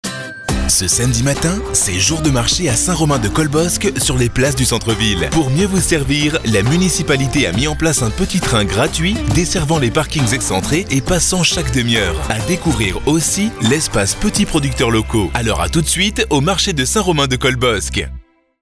4000 exemples de spots radio